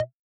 Index of /musicradar/retro-drum-machine-samples/Drums Hits/Raw
RDM_Raw_SY1-Perc04.wav